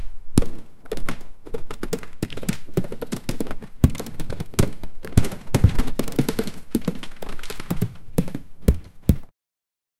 suara dentuman kaki kerumunan mammoth
suara-dentuman-kaki-kerum-3qnssnxj.wav